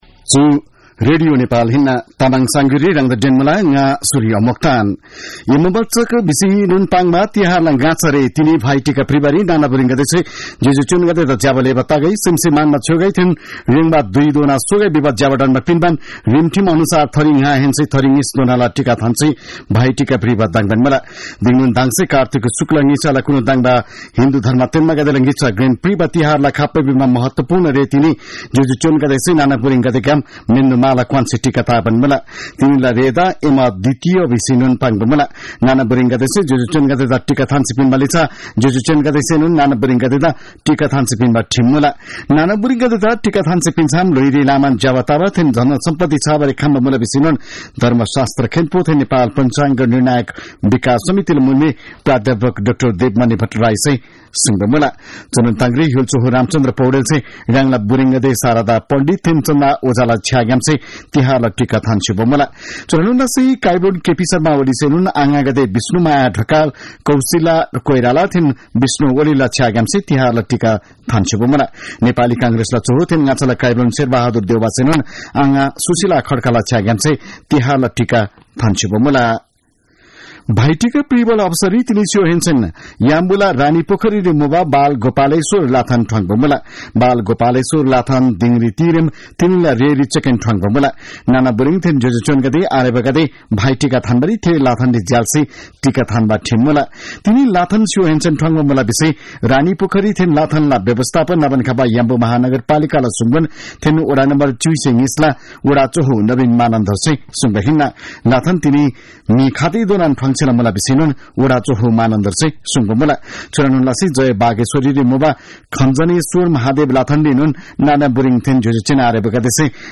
तामाङ भाषाको समाचार : १९ कार्तिक , २०८१